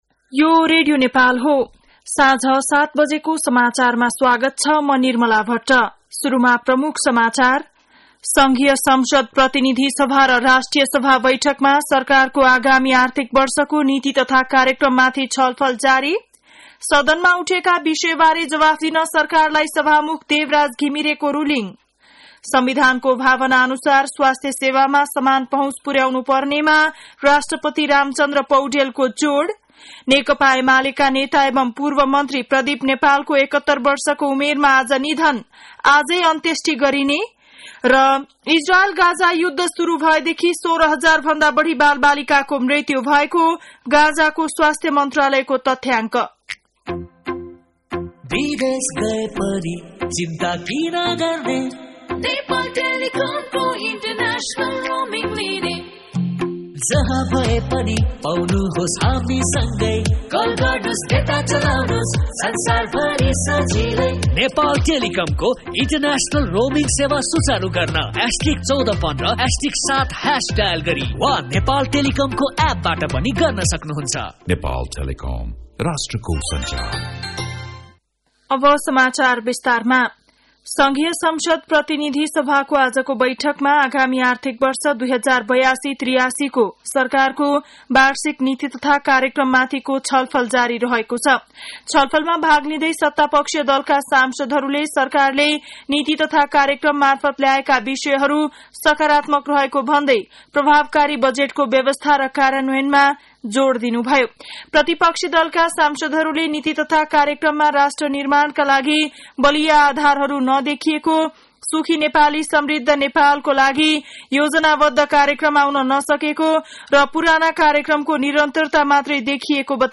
An online outlet of Nepal's national radio broadcaster
बेलुकी ७ बजेको नेपाली समाचार : २३ वैशाख , २०८२